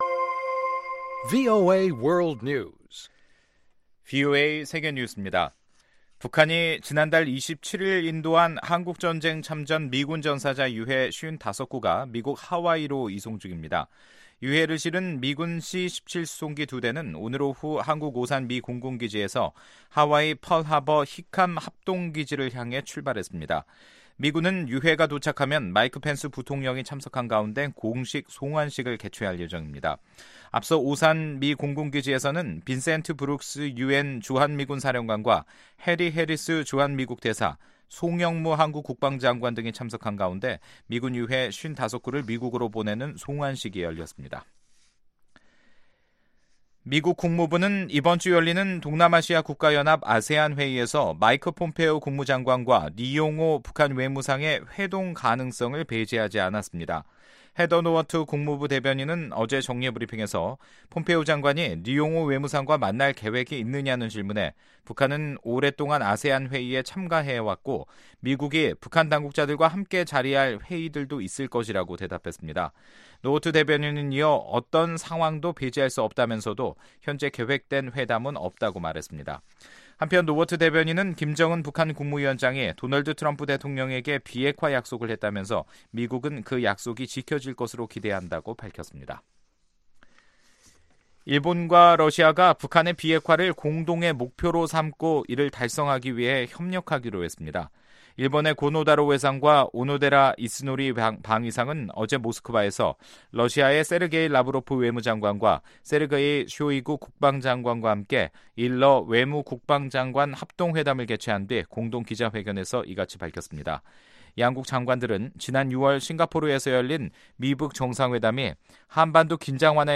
VOA 한국어 간판 뉴스 프로그램 '뉴스 투데이', 2018년 8월 1일 3부 방송입니다. 한국전쟁 당시 북한 지역에서 전사한 미군 유해 55구 송환식이 오늘 오산 미 공군기지에서 열렸습니다. 미국 국무부는 북한의 대륙간탄도미사일(ICBM) 개발 관련 보도와 관련해, 북한이 비핵화 약속을 지키기 바란다고 밝혔습니다.